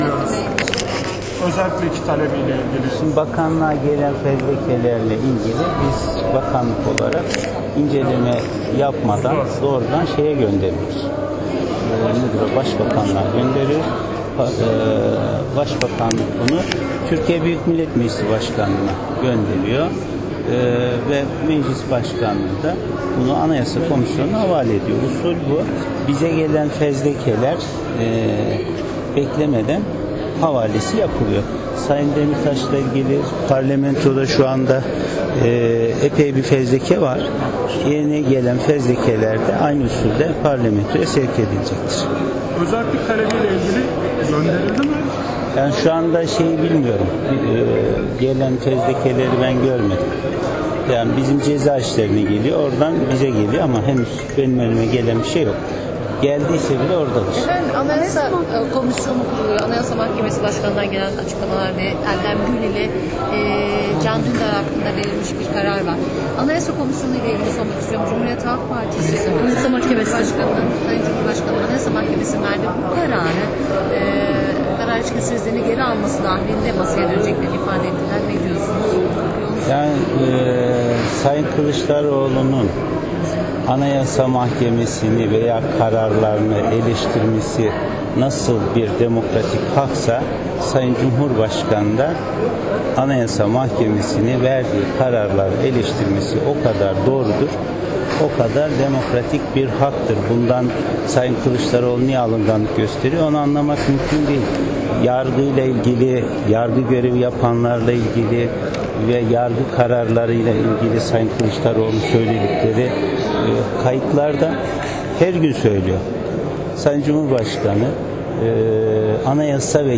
Bozdağ, TBMM’de gazetecilere yaptığı açıklamada, AYM’nin kararı için “anayasa ve yasa ihlali olduğu açık olan bir karar” yorumuyla dikkat çekti.
Adalet Bakanı Bekir Bozdağ'ın açıklaması